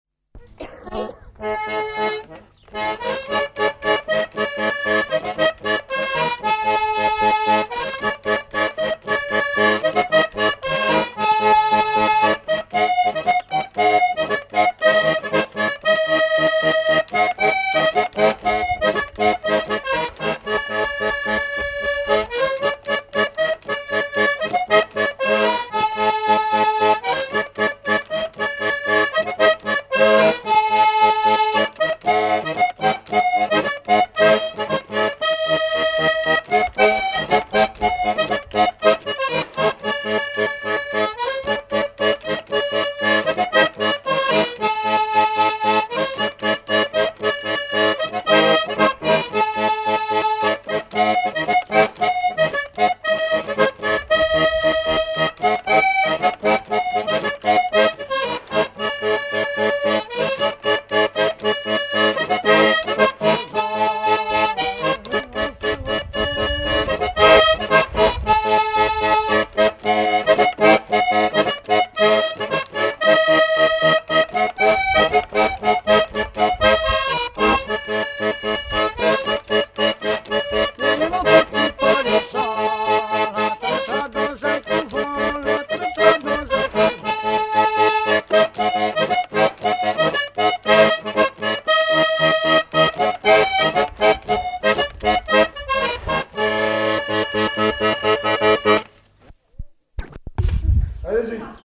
Localisation Epine (L') (Plus d'informations sur Wikipedia)
Usage d'après l'analyste circonstance : fiançaille, noce ;
Catégorie Pièce musicale inédite